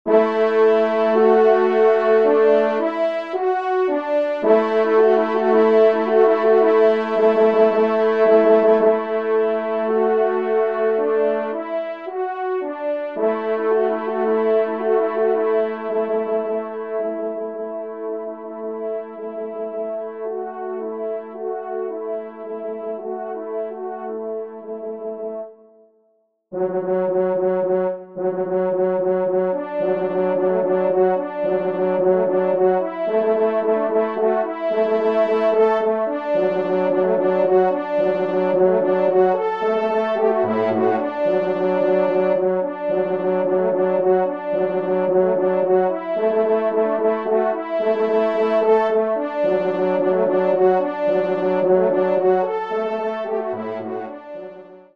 3ème Trompe